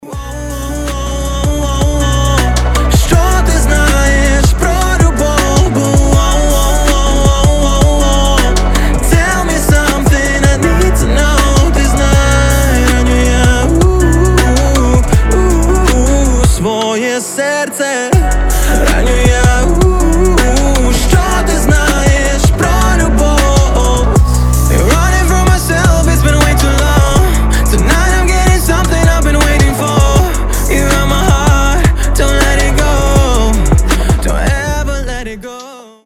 • Качество: 320, Stereo
лирика
красивый мужской голос
RnB